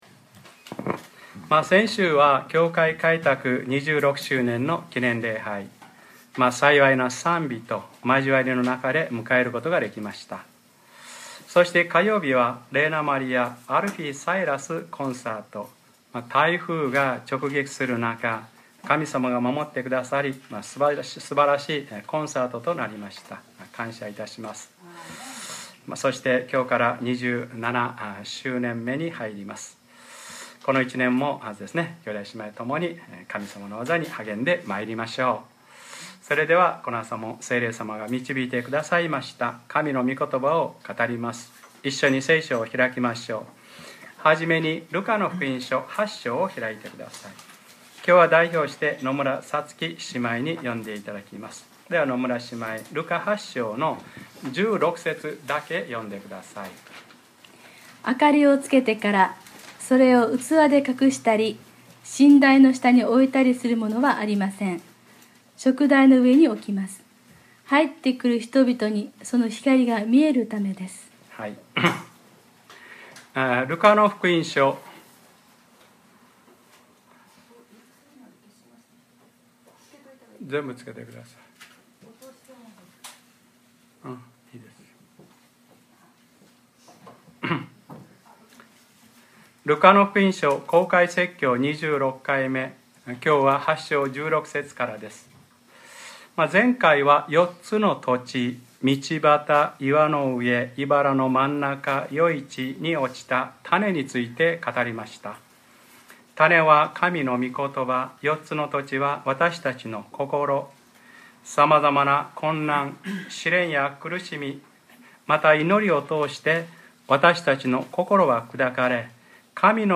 2013年10月13日(日）礼拝説教 『ルカｰ２６ イエスはぐっすり眠ってしまわれた』